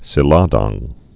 (sĭ-lädäng)